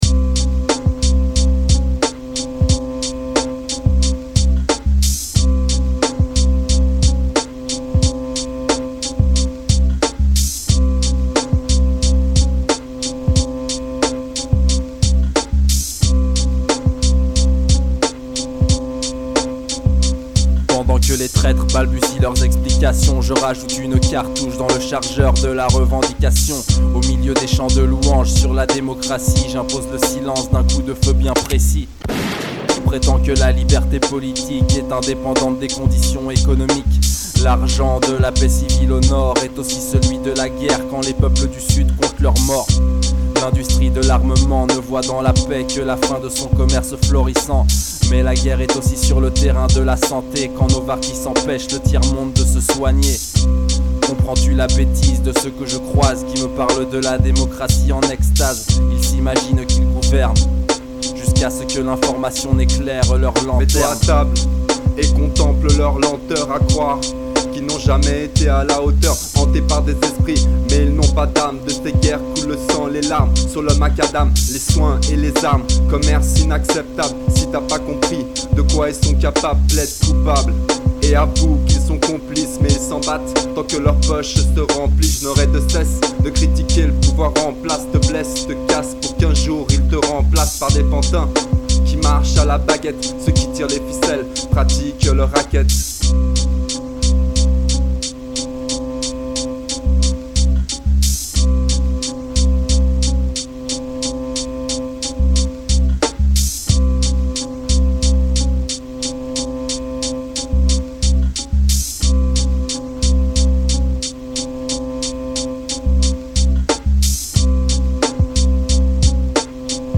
mc